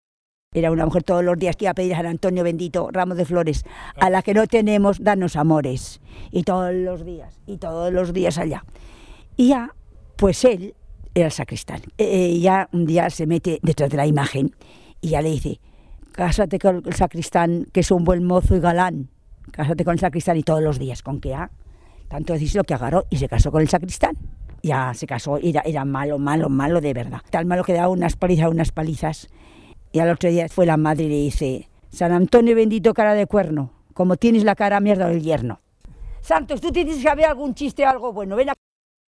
Clasificación: Cuentos
Lugar y fecha de recogida: El Redal, 17 de julio de 2003